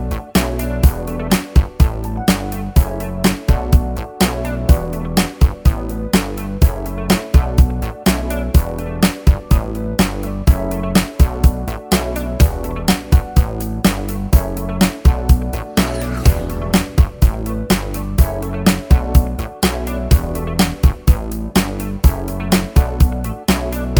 no Backing Vocals or vocoder Disco 4:11 Buy £1.50